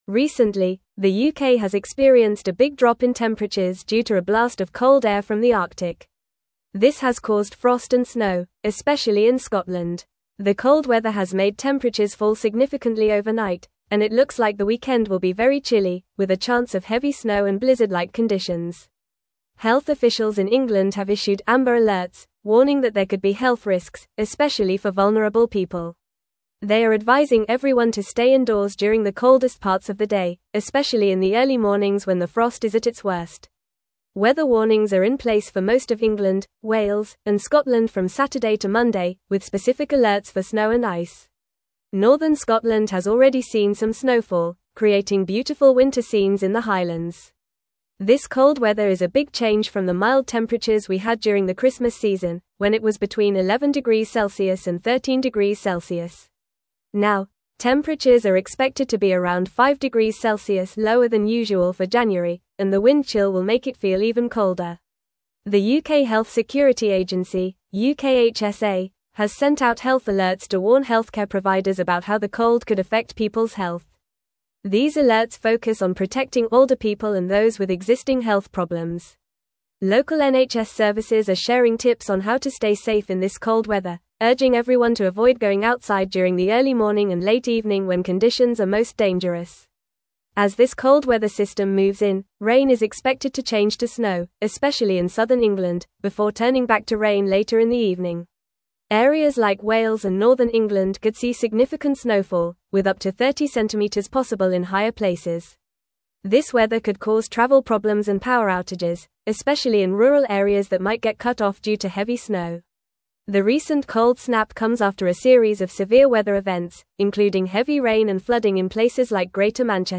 Fast
English-Newsroom-Upper-Intermediate-FAST-Reading-UK-Faces-Bitter-Cold-and-Heavy-Snow-This-Weekend.mp3